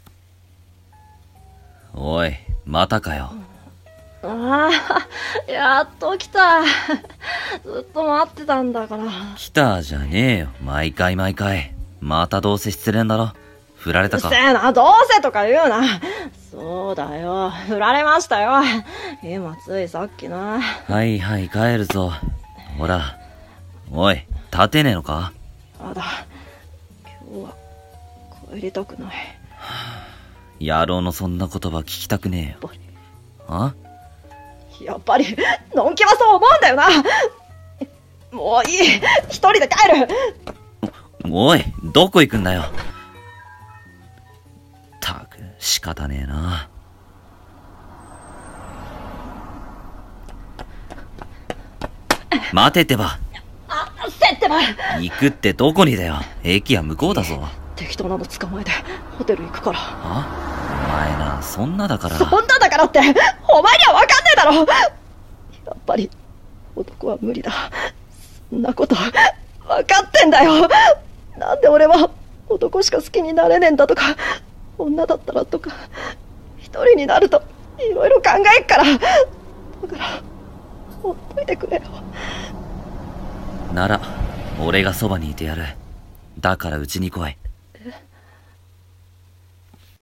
【BL声劇】アイビーが根付く前に【ドラマCD風】